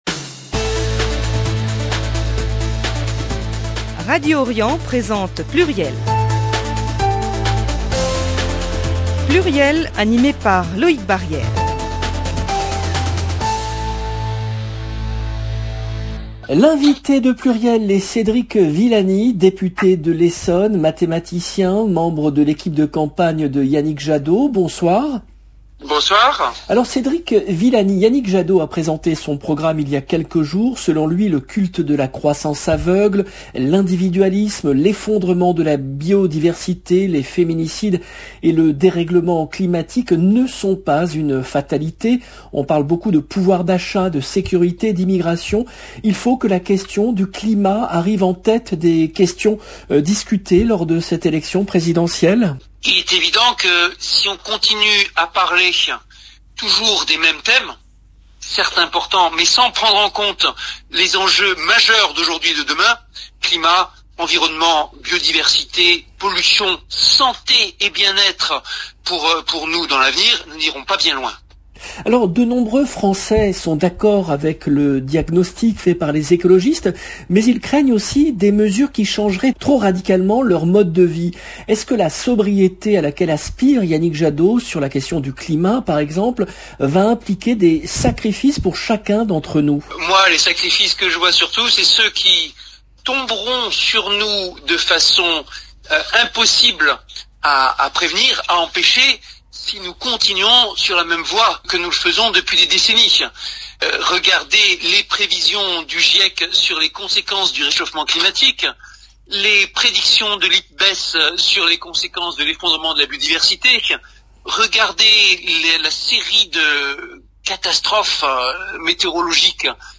L’invité de PLURIEL est Cédric Villani , député de l’Essonne, mathématicien, membre de l’équipe de campagne de Yannick Jadot